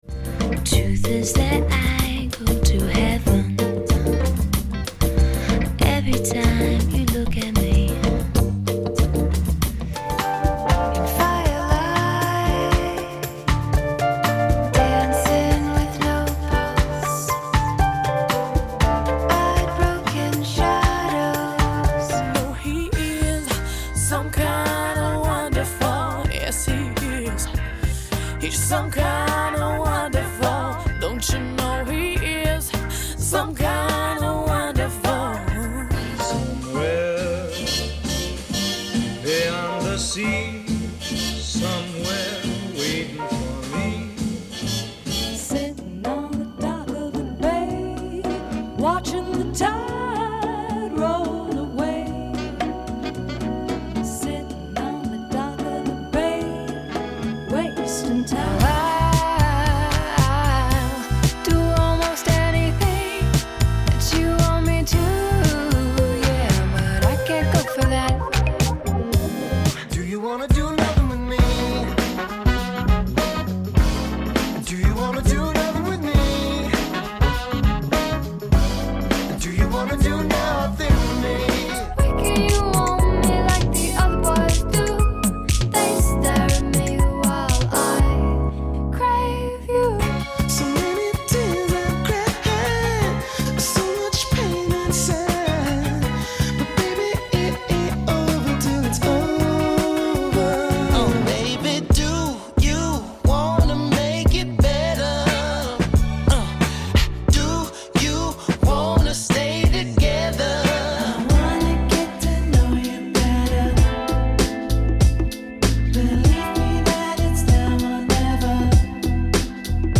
Mixed Tempo